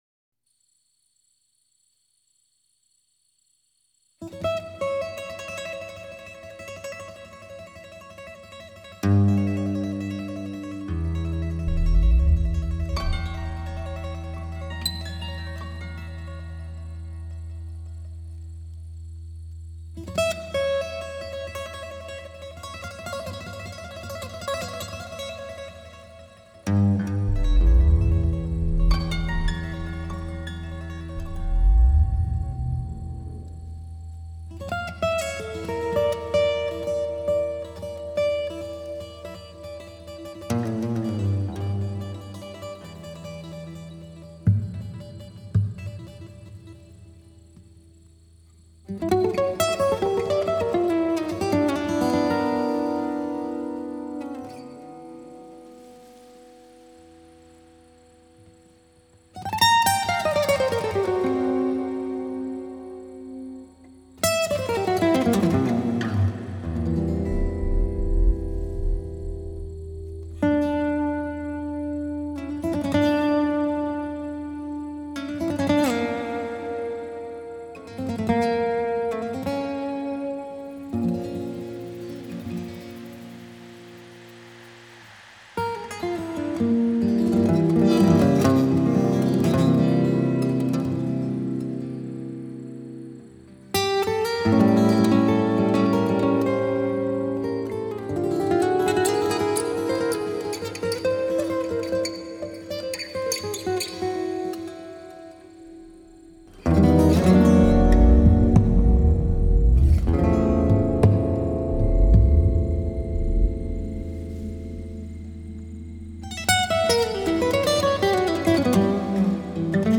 Славянская музыка